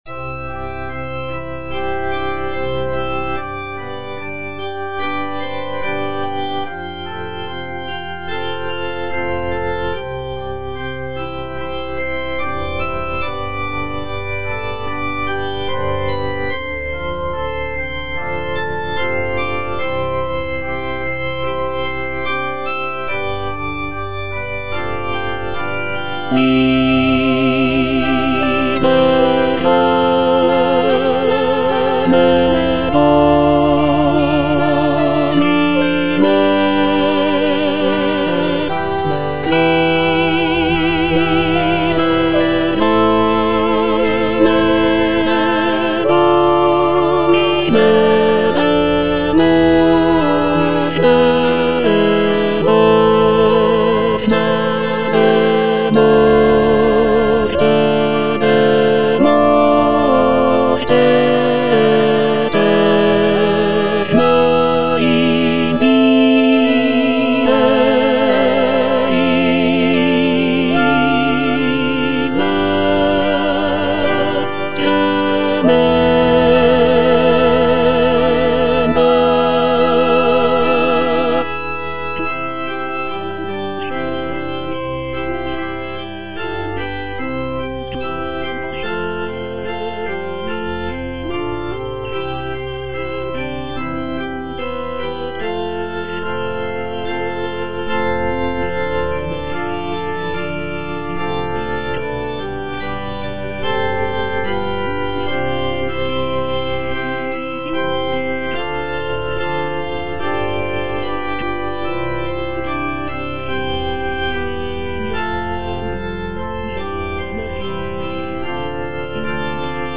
is a sacred choral work rooted in his Christian faith.
Tenor (Emphasised voice and other voices) Ads stop